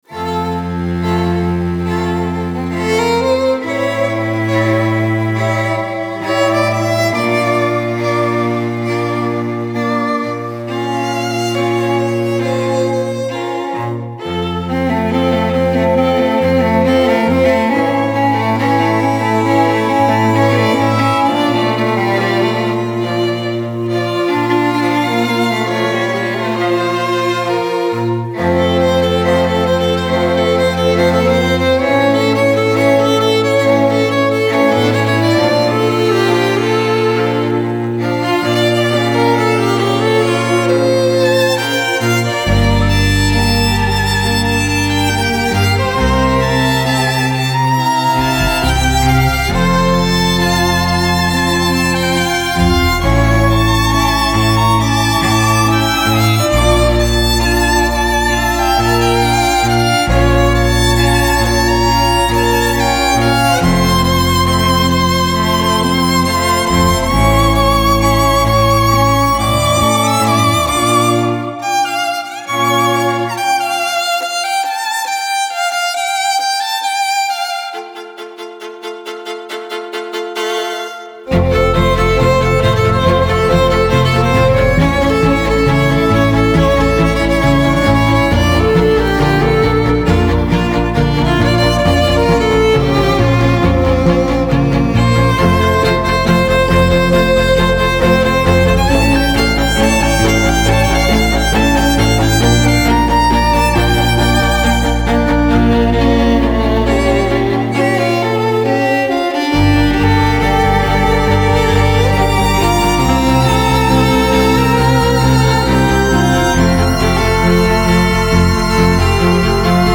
Classical Crossover
ویولن
سازهای زهی